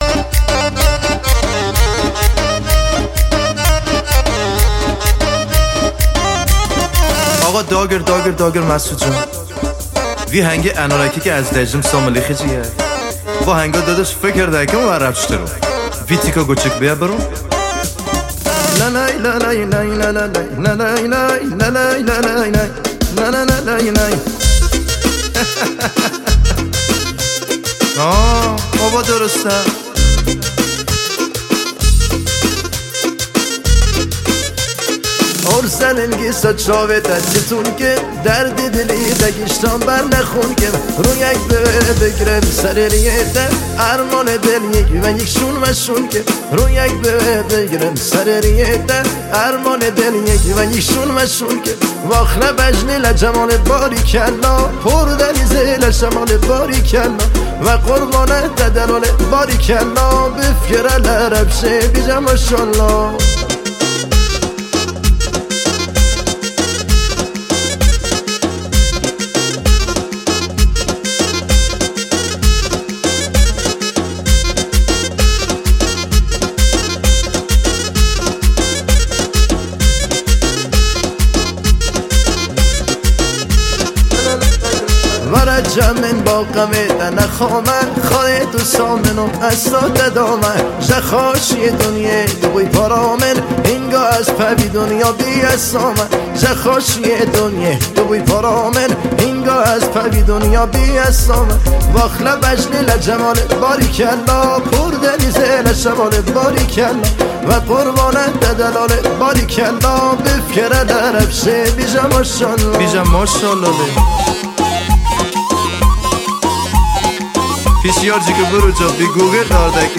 موزیک کرمانجی